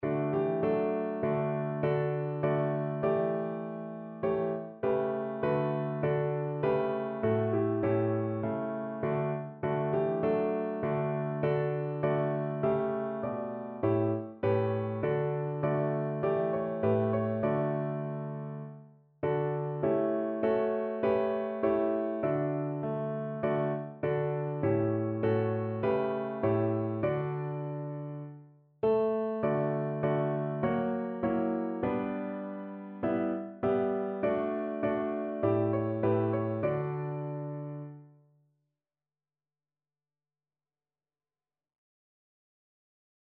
Notensatz 1 (4 Stimmen gemischt)
Musik: Wilhelm Brockhaus (*1819 †1888)
• gemischter Chor mit Akk.